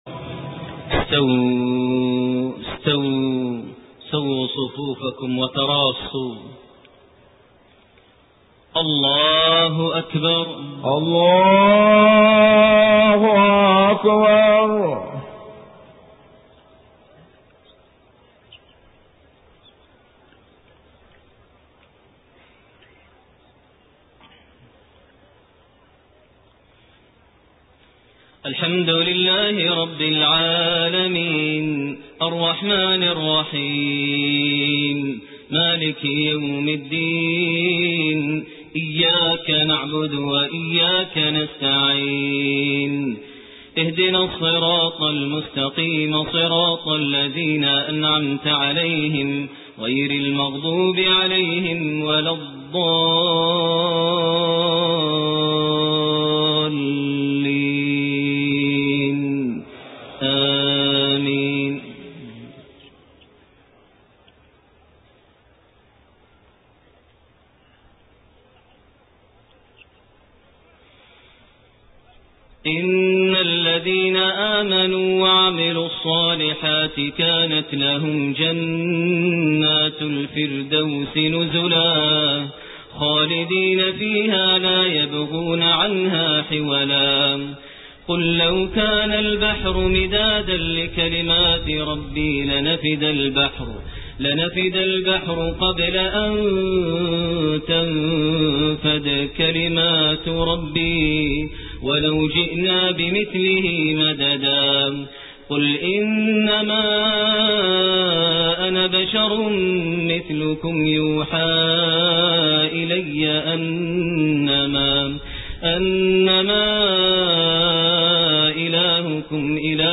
Maghrib prayer from Surat Al-Kahf and Maryam > 1428 H > Prayers - Maher Almuaiqly Recitations